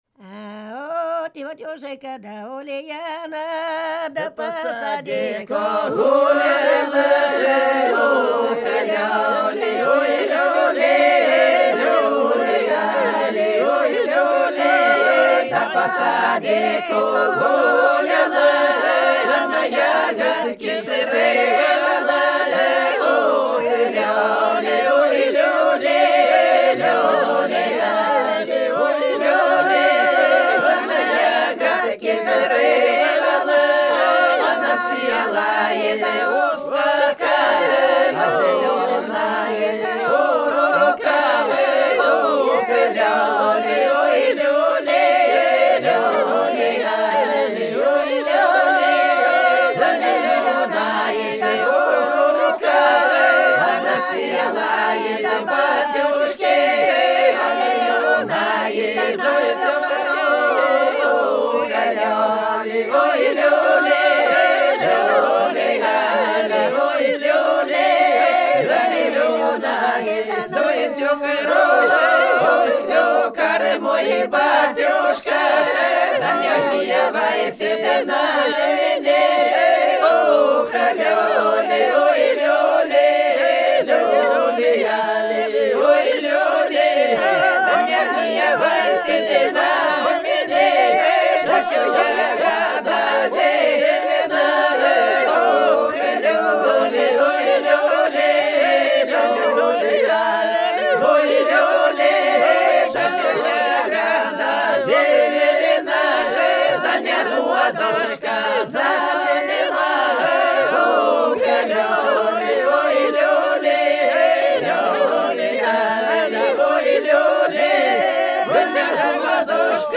Ансамбль села Хмелевого Белгородской области Тетушка Ульяна (величальная плясовая накануне пропоя)